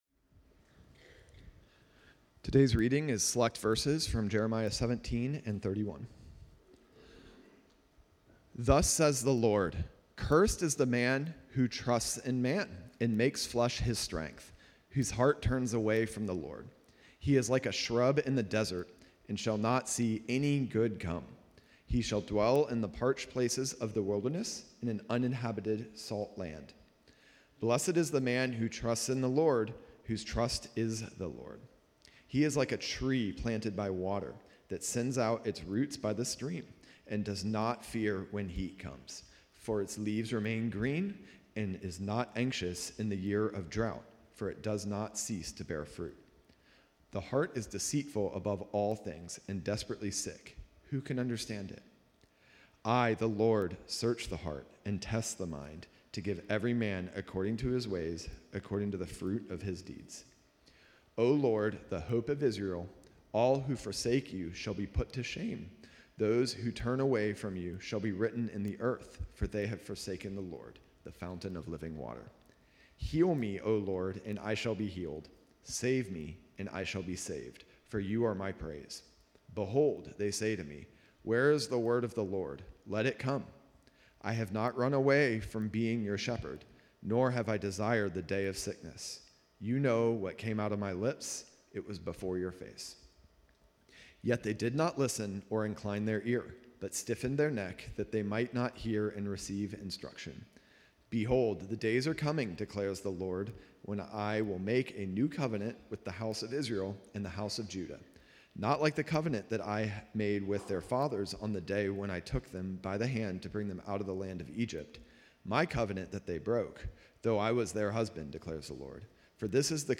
Online Service - Bridges Community Church Los Altos